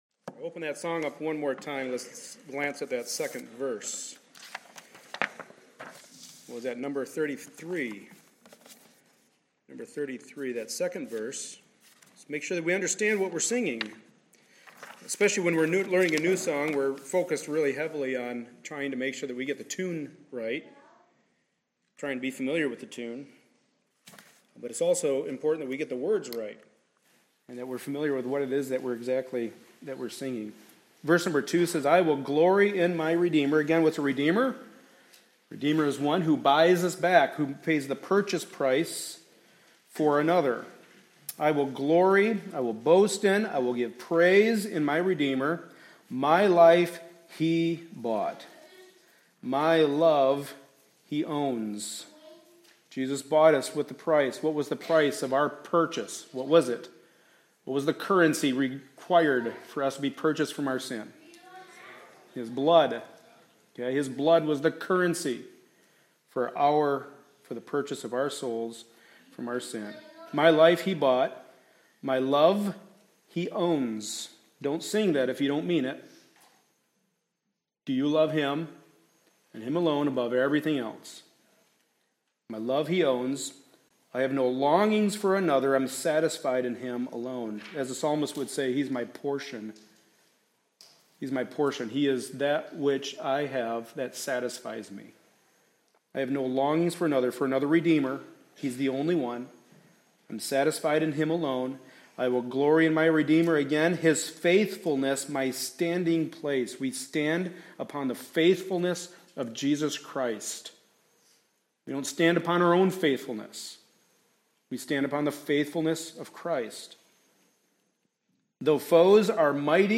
2 Timothy 1:15-18 Service Type: Sunday Morning Service A study in the Pastoral Epistles.